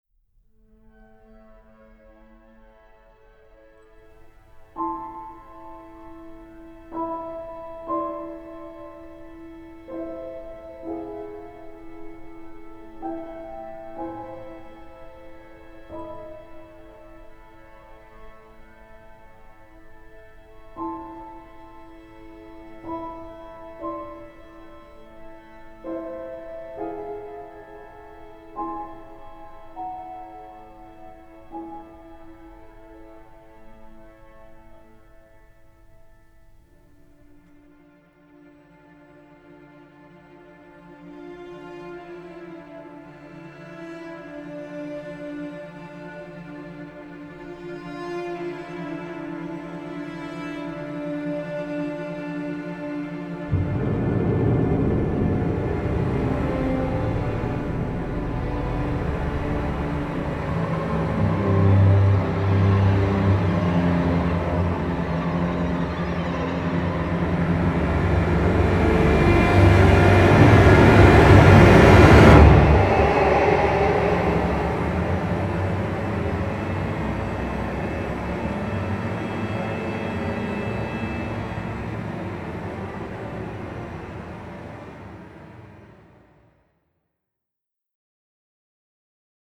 Genre : Soundtrack